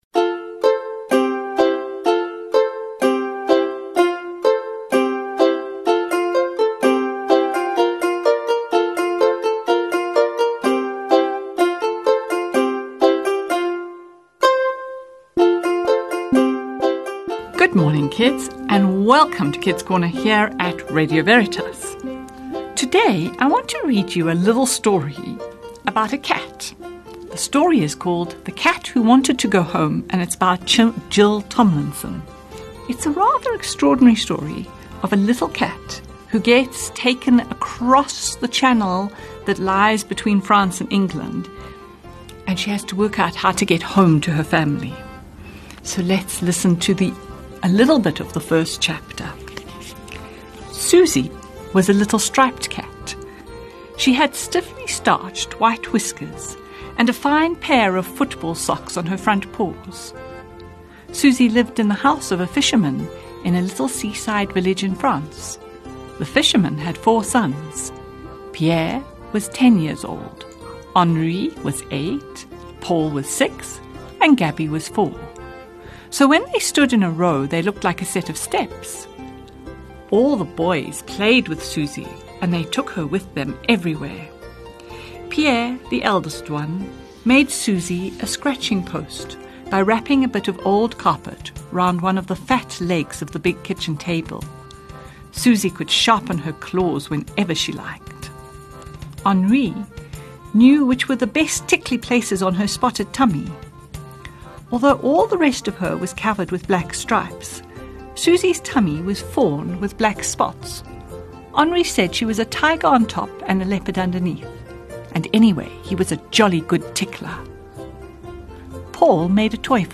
Let's read: The cat who wanted to go home by Jill Tomlinson